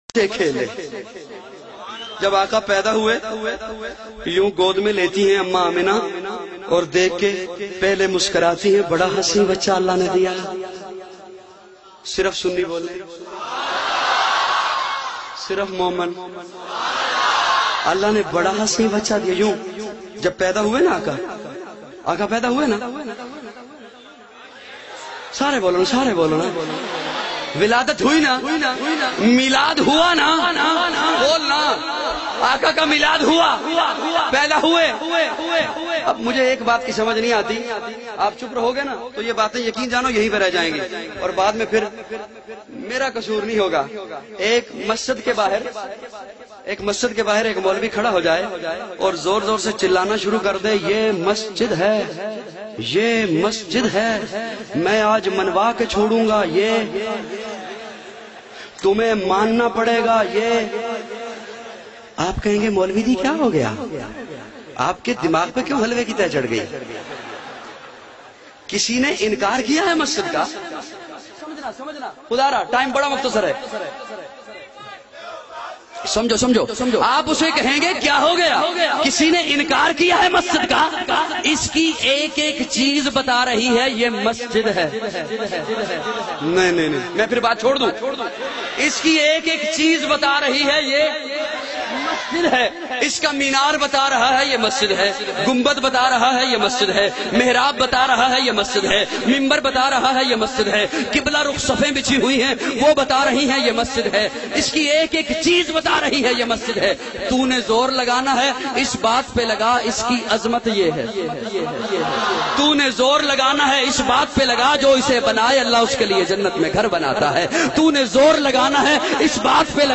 Milad Un Nabi 12 rabi ul awal byan mp3